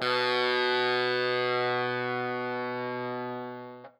SPOOKY    AE.wav